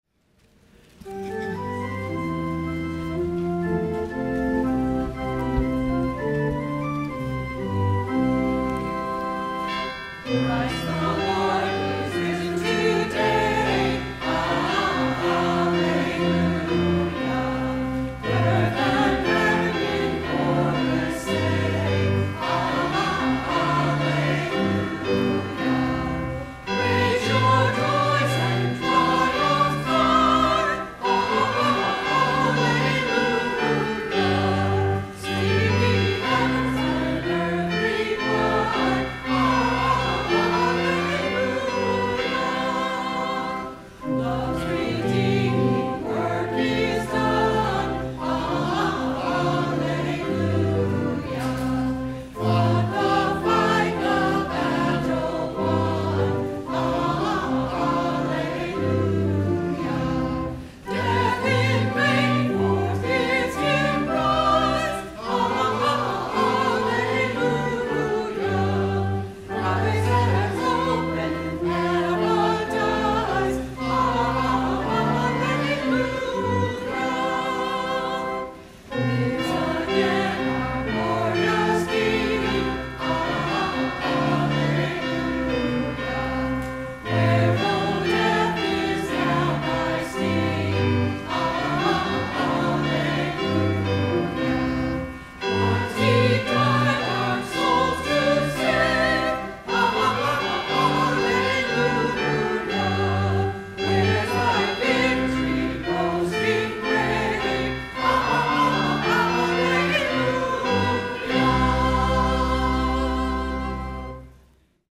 Sung by the Church and Choir.